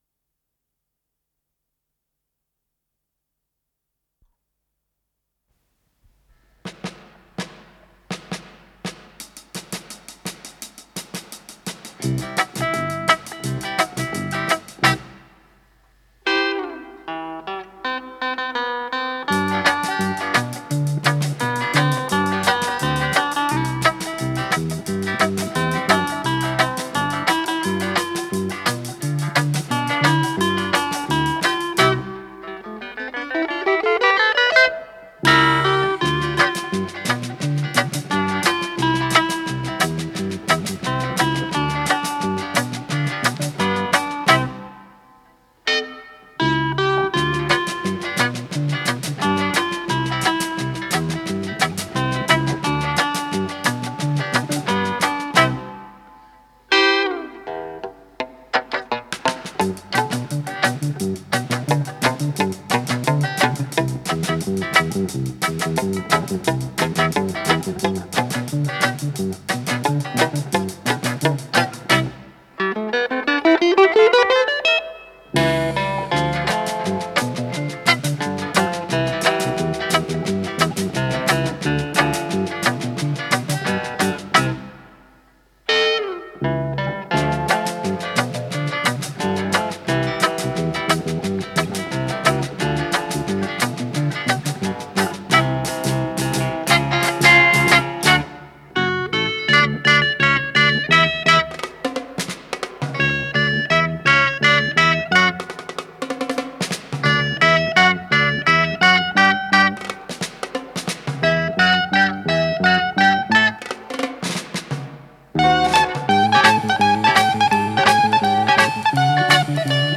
электрогитара
электробас
ударные